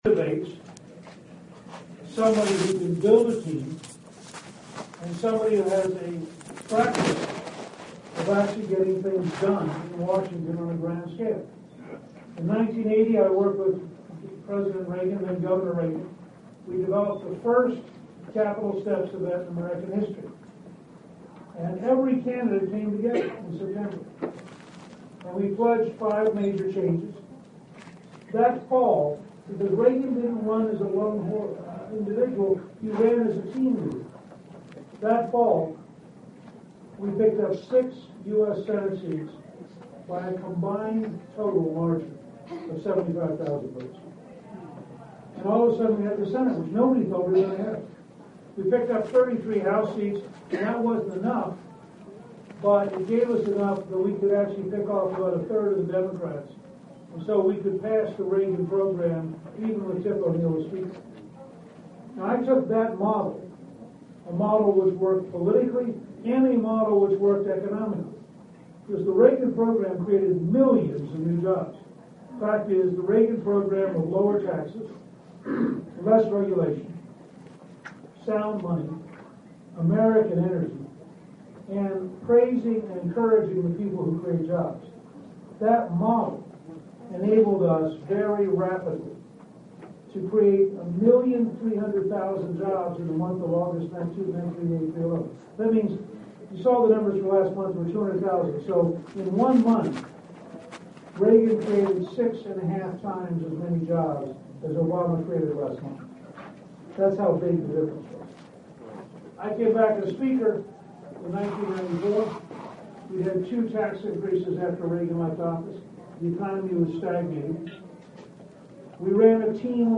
That truism told me by a reporter about political candidates may be about all that I found in common between my experience last year when I saw Michele Bachmann speak at the Beacon Drive In and today when I got to hear Newt Gingrich at the same upstate South Carolina landmark.
Because he was running so late, Gingrich only took two questions.
I got the chance to shake hands with Gingrich as he arrived accompanied by his wife and the crowd shouting “Newt! Newt! Newt!” and I found his grip fleshy and weak, but not sweaty or cold.